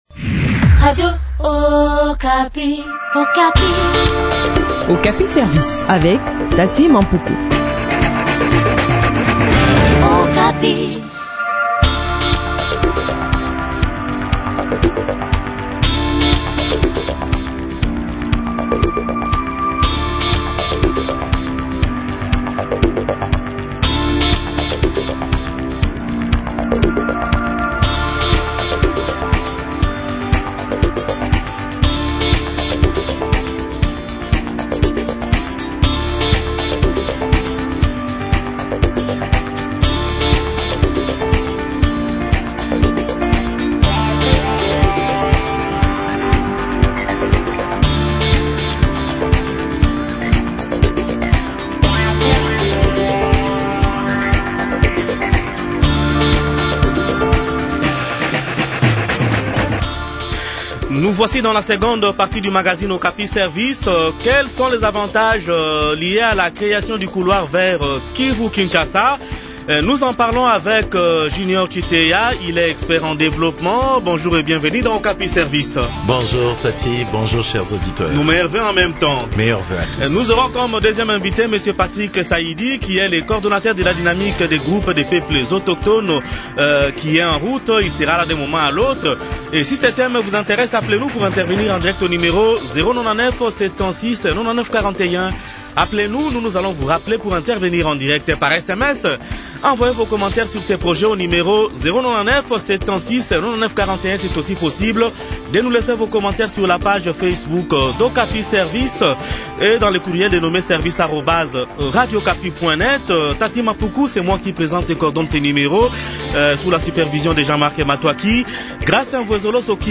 expert en développement durable et environnement.